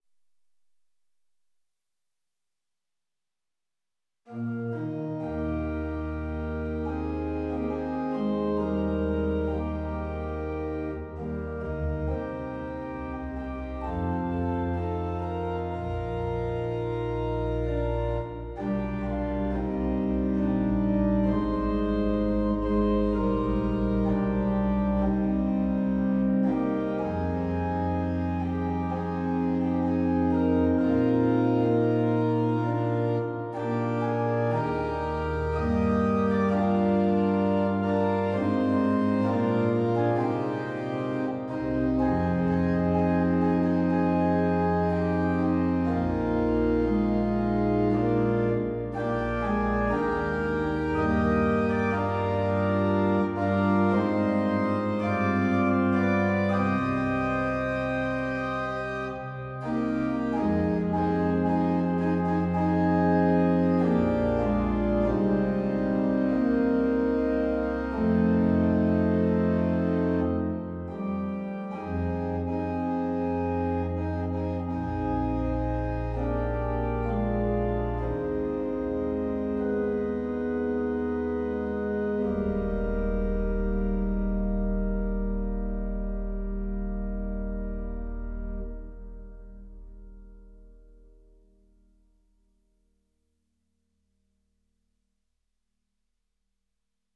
a beautiful hymn-style original composition